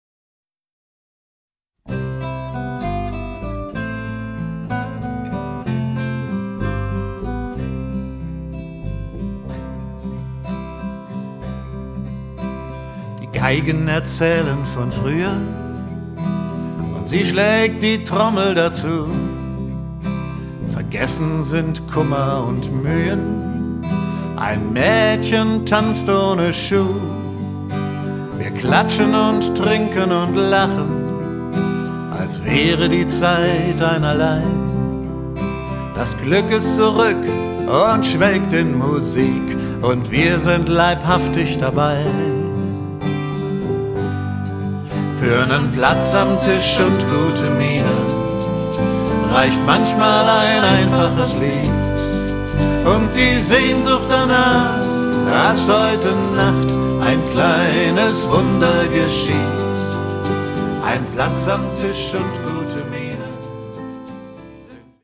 Piano, Keyboards
Percussion
Vocals, Gitarren